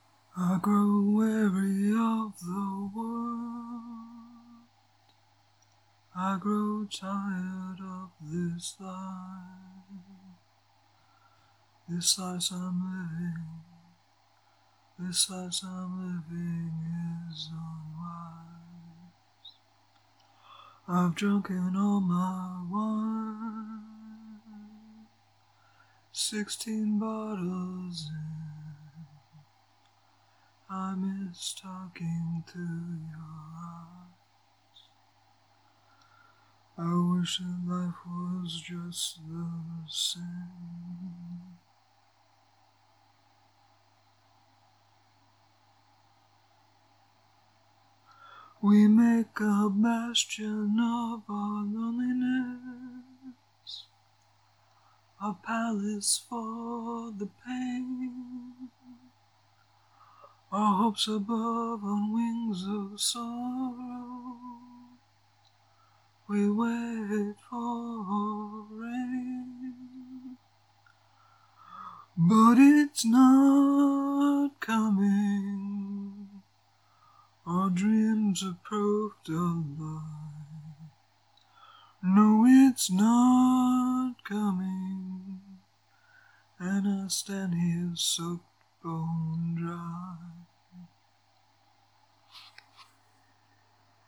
Vocal only.